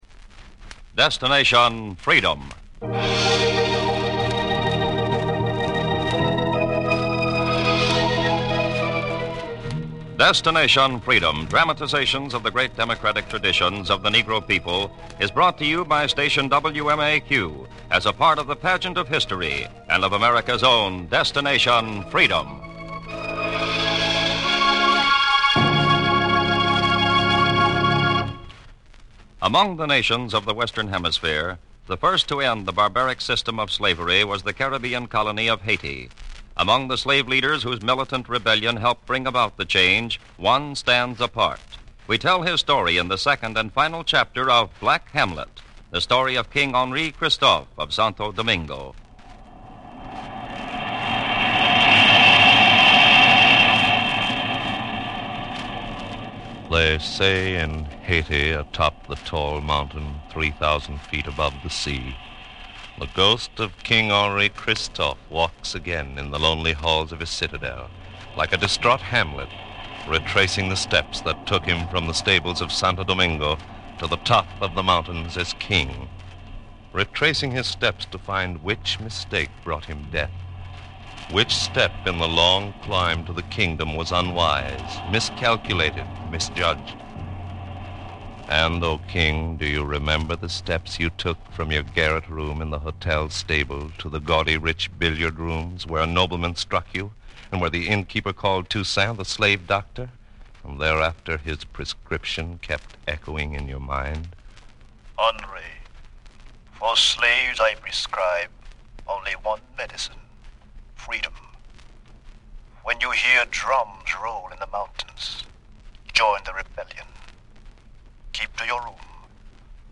The series was known for its dramatizations of the lives of notable African Americans and their contributions to society.